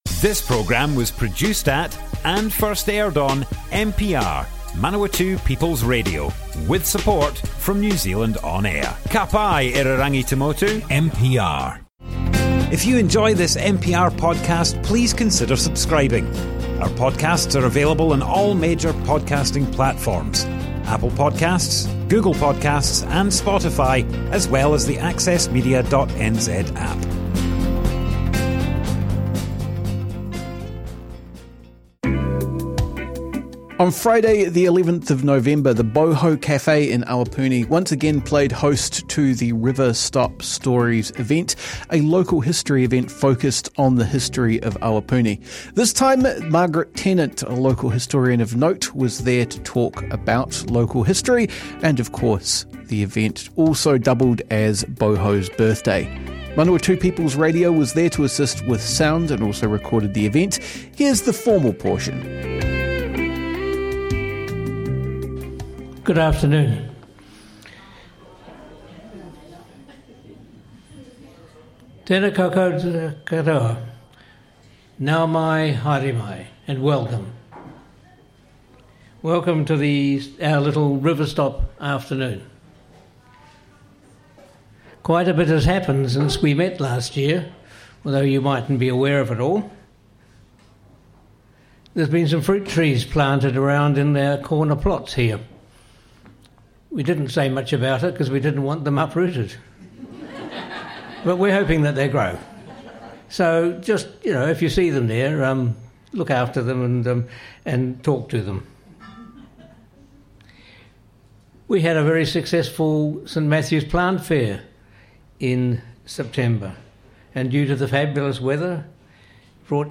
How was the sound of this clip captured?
speaks about the history of the Awapuni Hospital at an Awapuni Local History week event. The talk was arranged by River Stop Awapuni, a community group, and followed by afternoon tea to celebrate the 6th birthday of Boho Cafe.